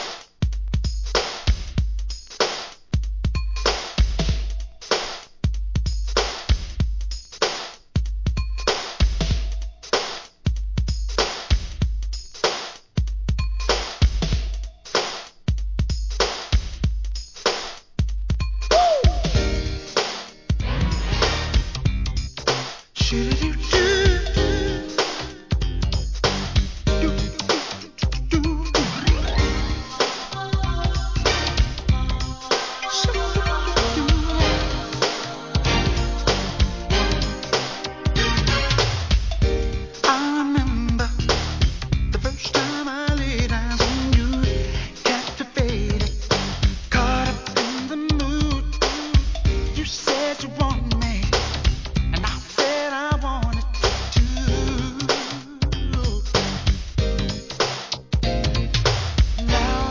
HIP HOP/R&B
メロ〜ミディアムが充実の内容!!